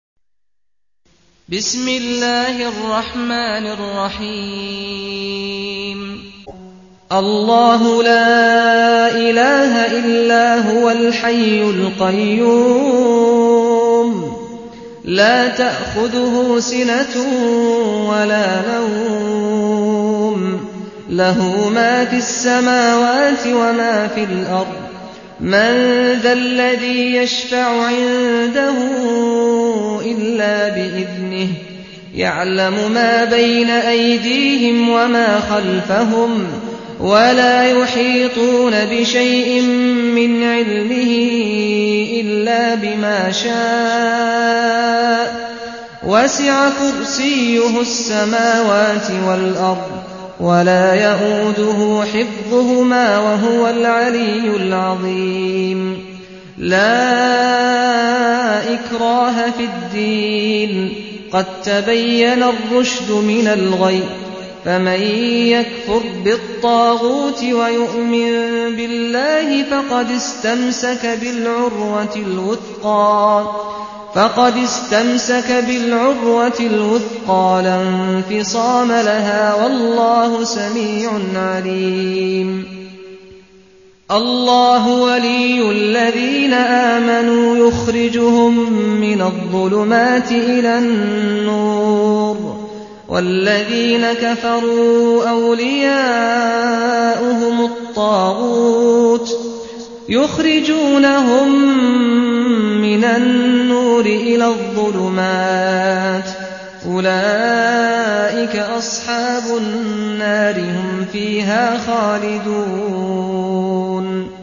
صوت ایت الکرسی با صدای سعد الغامدی